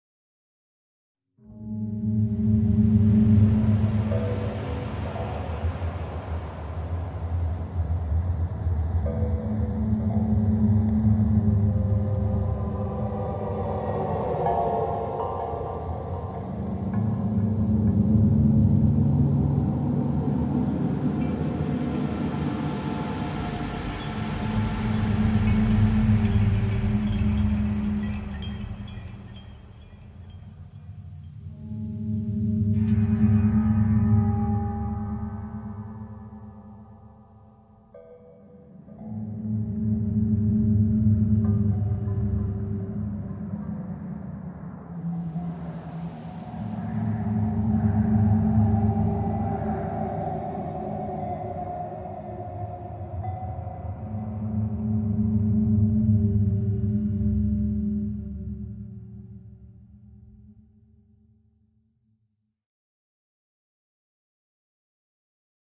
gp_horror_3.mp3